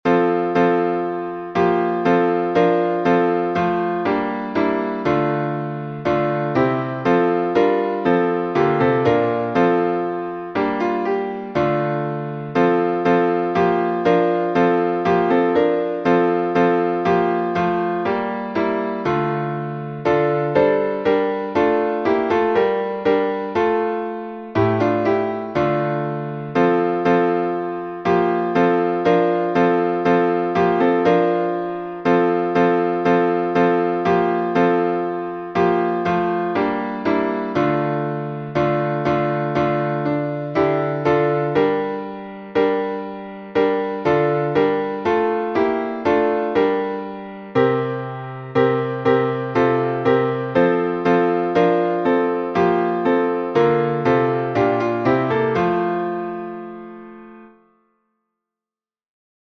Сербский напев, глас 2